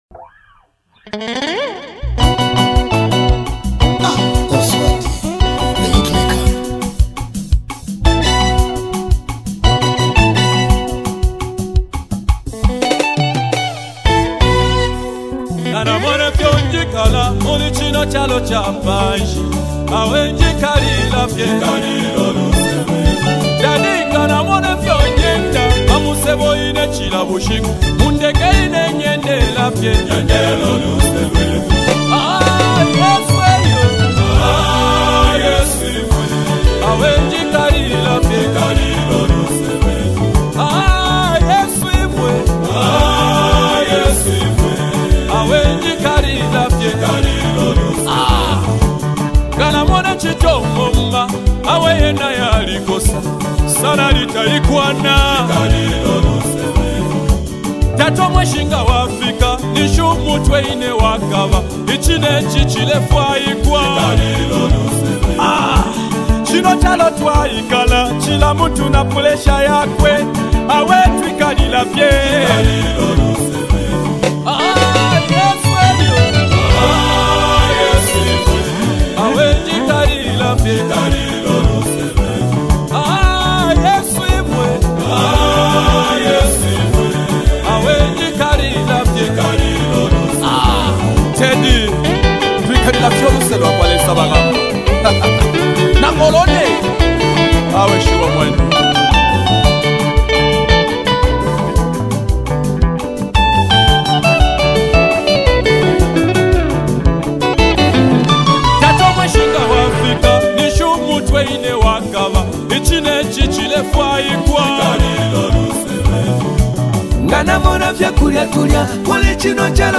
a dynamic sound that is both uplifting and deeply inspiring
With its catchy melodies and profound message